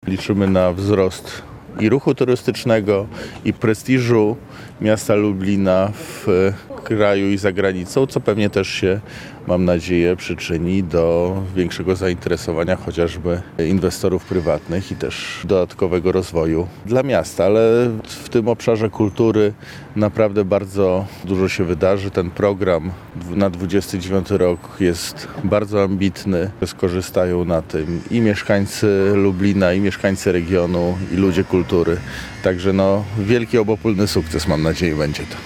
W Lublinie obraduje Komisja Kultury, Dziedzictwa Narodowego i Środków Przekazu. Tematem posiedzenia jest Europejska Stolica Kultury 2029 – ten tytuł przypadł Lublinowi.
– Jest kilka korzyści dla Lublina z tego tytułu, przede wszystkim pod względem rozwoju instytucji i ludzi kultury – mówi wiceminister kultury i dziedzictwa narodowego Marek Krawczyk.